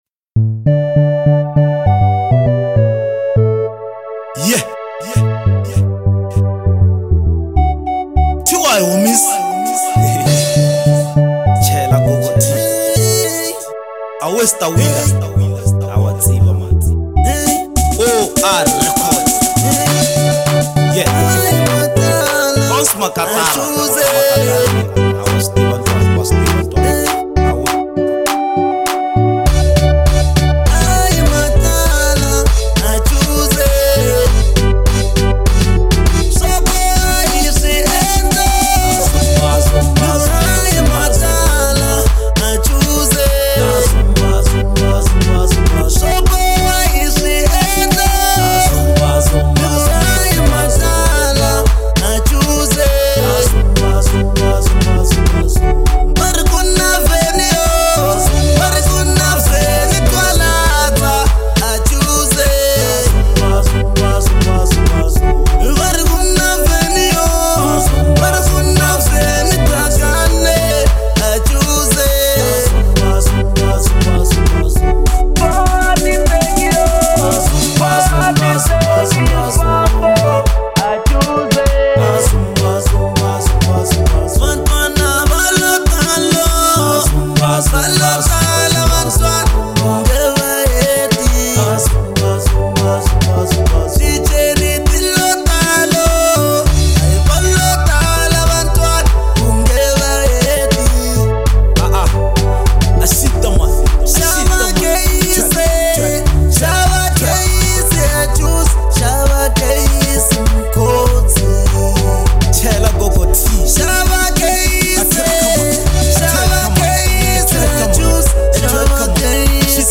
04:37 Genre : Afro Pop Size